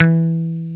Index of /90_sSampleCDs/Roland LCDP02 Guitar and Bass/GTR_Dan Electro/GTR_Dan-O 6 Str